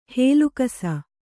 ♪ hēlu kasa